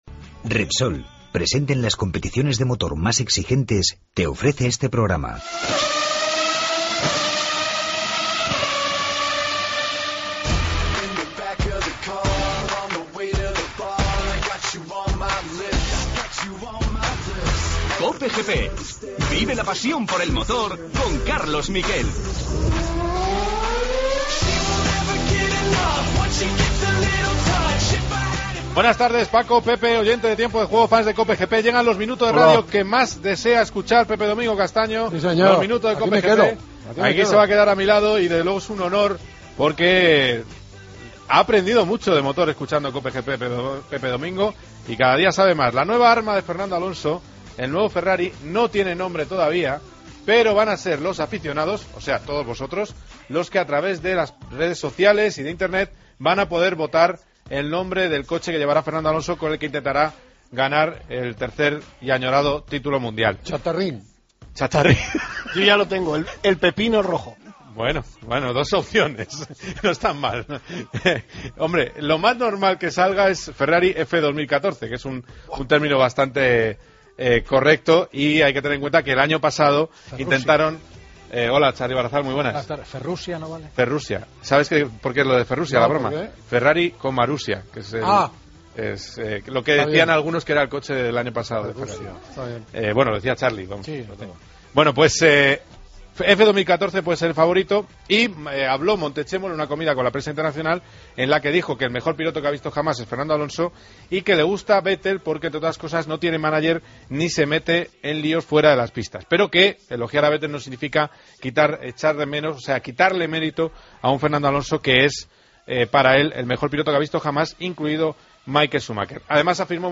Además, le preguntamos sobre el número que llevará y sus esperanzas de victoria con Ferrari en 2014. Entrevista a Laia Sanz, que prepara su Dakar más ambicioso.